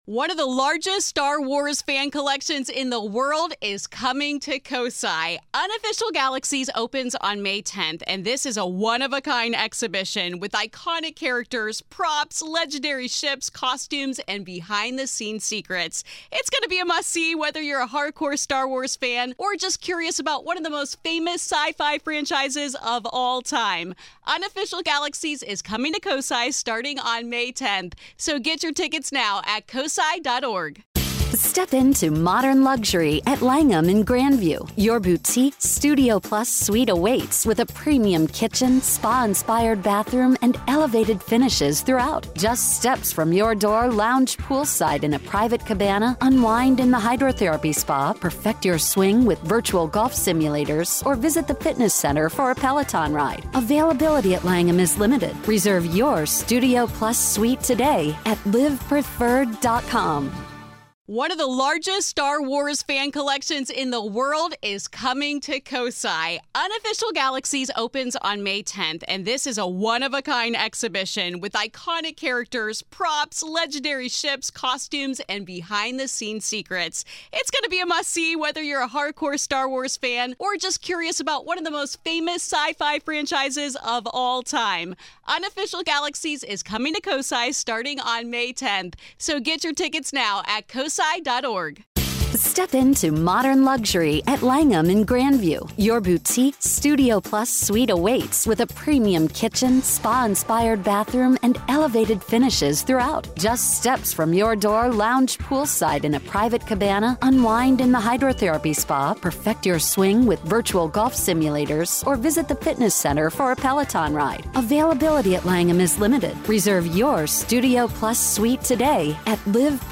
This is Part Two of our conversation.